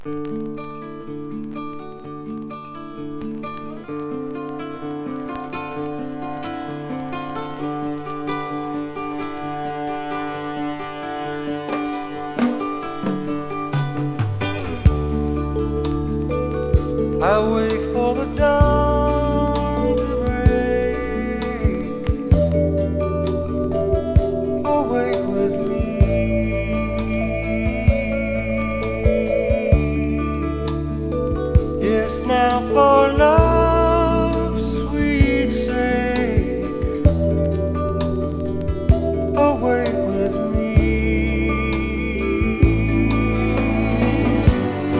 以降、変わること無く、真摯な印象で、甘く伸びやかなヴォーカルが魅力です。
時代を感じさせるポップスやロックに、洗練されたストリングスアレンジも冴える、香り立つような良盤です。
Rock, Pop, Jazz, Folk　USA　12inchレコード　33rpm　Stereo